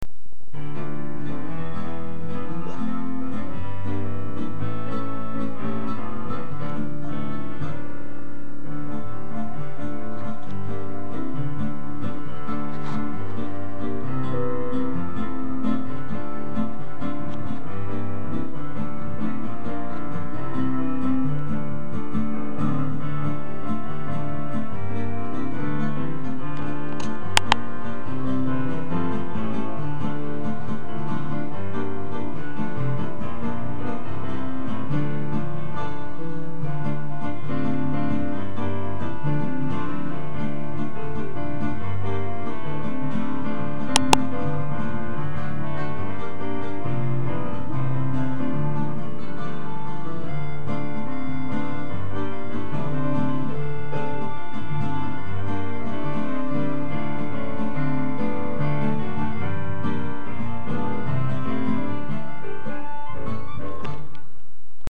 הקלטה של כמה ערוצים באורגן
יש זמנים מסויימים שיש תחושה של בלגן, אי סדר. יש רעשי רקע בהקלטה.